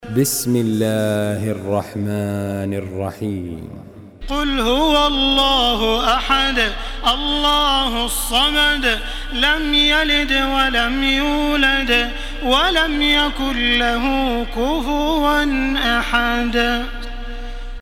سورة الإخلاص MP3 بصوت تراويح الحرم المكي 1434 برواية حفص
مرتل